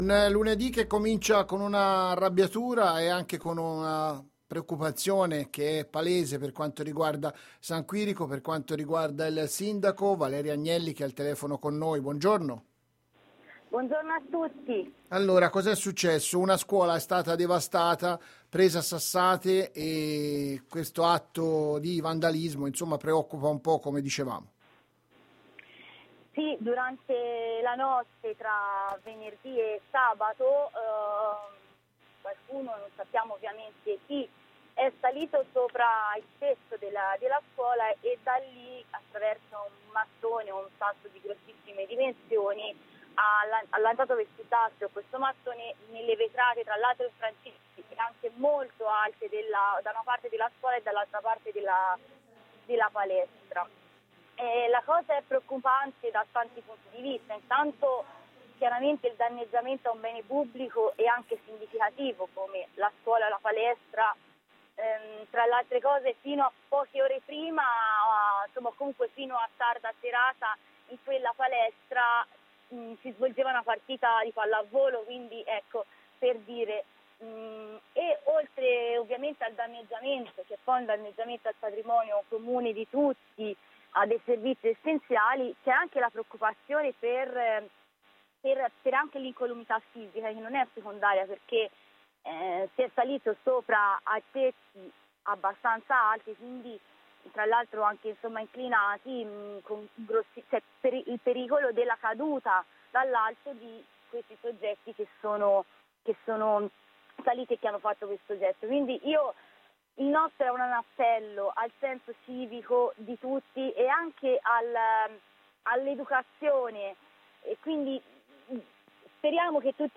Scuola presa a sassate, atto di vandalismo a San Quirico che preoccupa, ne parla il Sindaco Valeria Agnelli